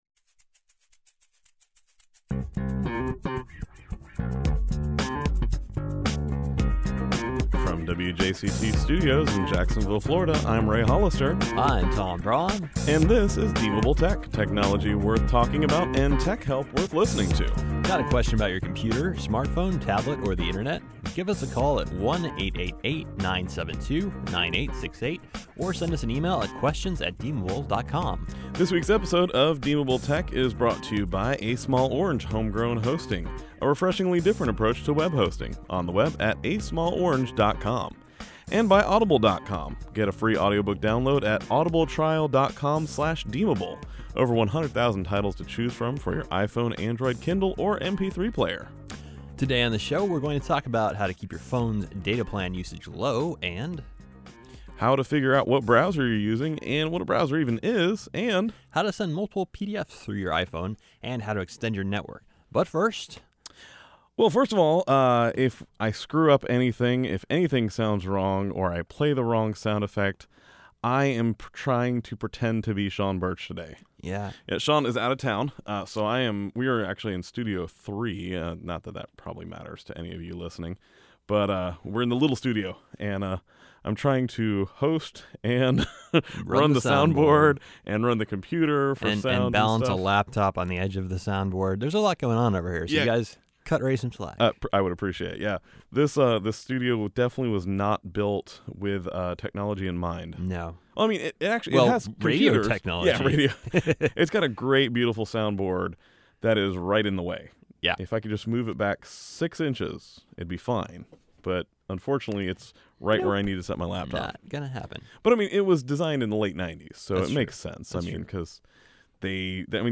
He almost pulled it off, but mostly through the magic of post production editing. On today’s episode we talk about how to keep your phone data usage low, how to download a new browser, how to install Adobe Acrobat even if you can’t see the installation page, and whether you should buy an bigger iPhone 4S or 5.